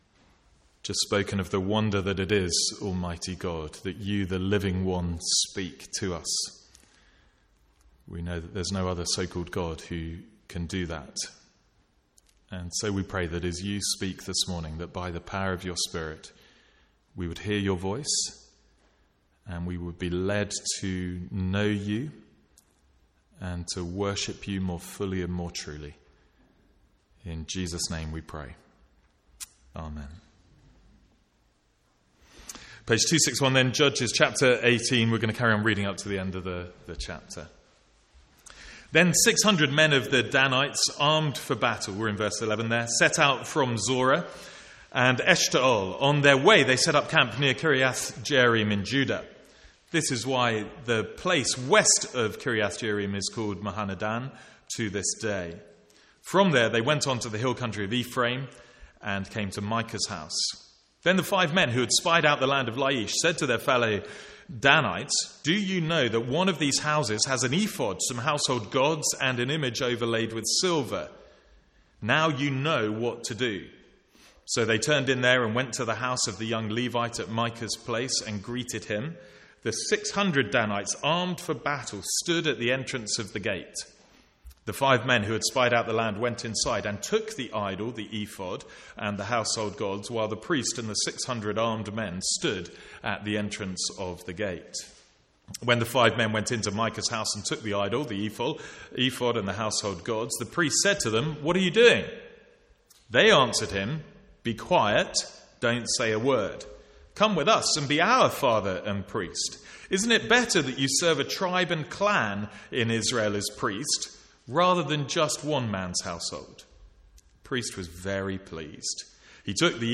Sermons | St Andrews Free Church
From the Sunday morning series in Judges.